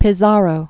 (pĭ-zärō, pē-thärō, -sär-), Francisco 1475?-1541.